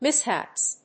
/ˈmɪˌshæps(米国英語)/